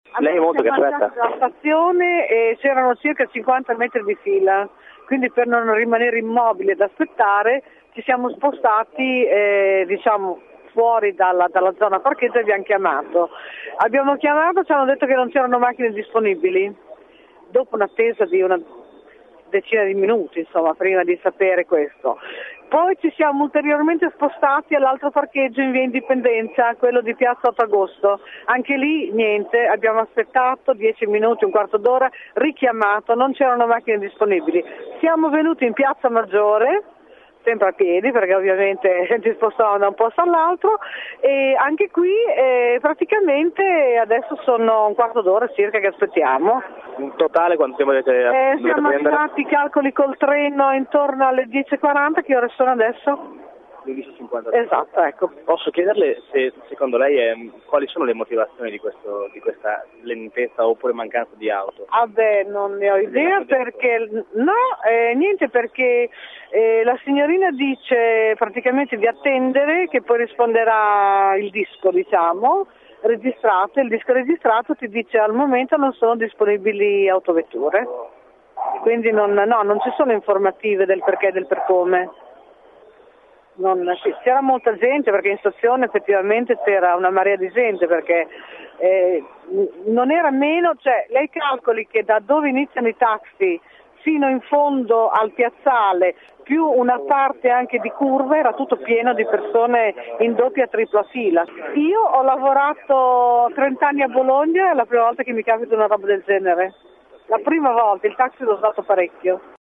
Poco prima delle 12, in piazza Re Enzo: